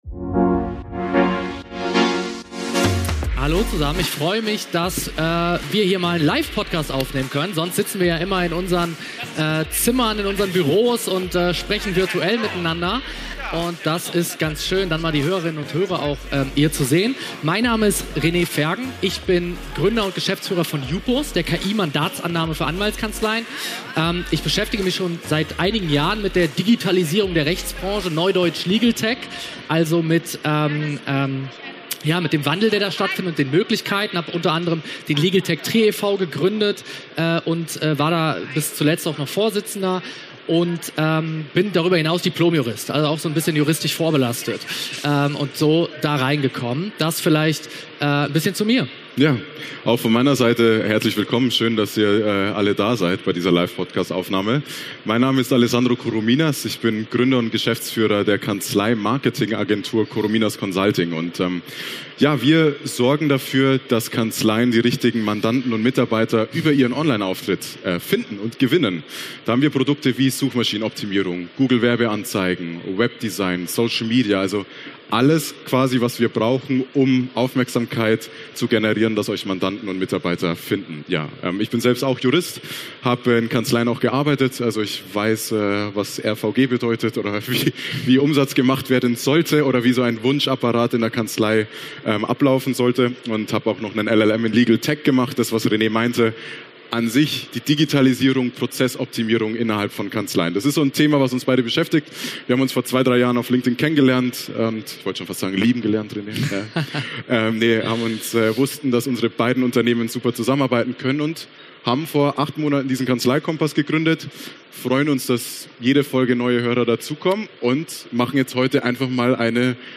KI und die Zukunft der Anwaltsarbeit - Live von der RA-Expo